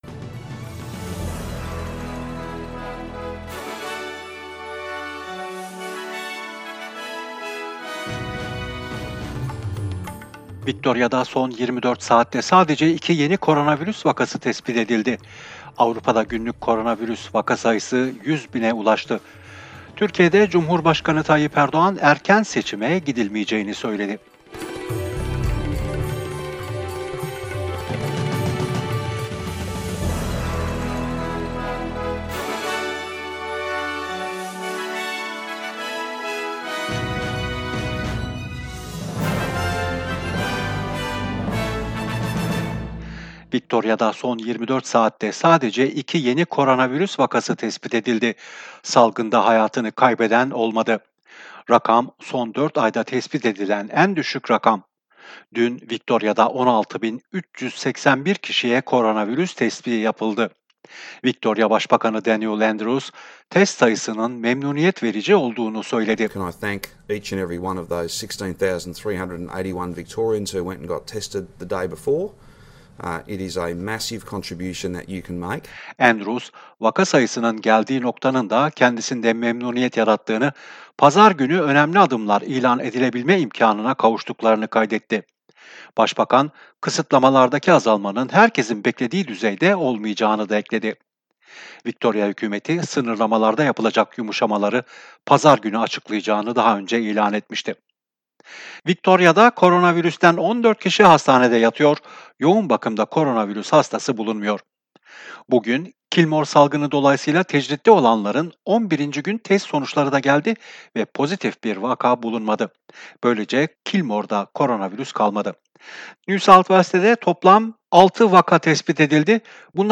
SBS Türkçe Haberler 16 Ekim